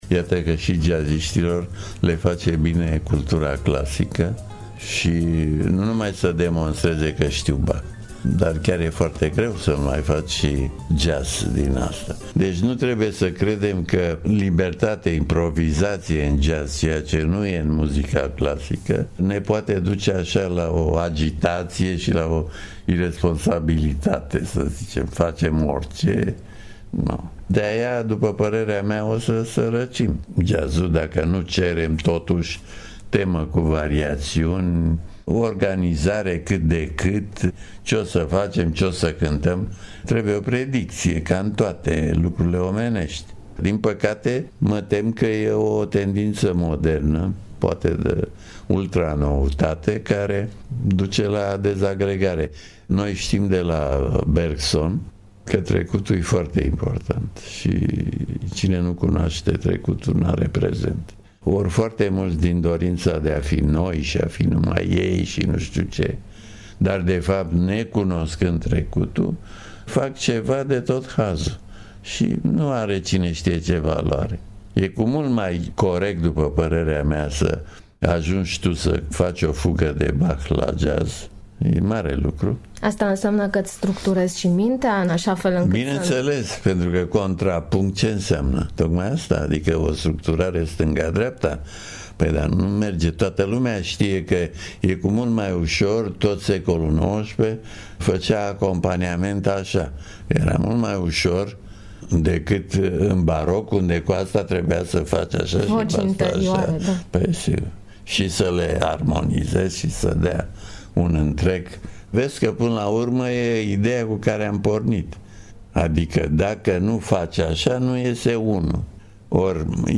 Fragment din interviul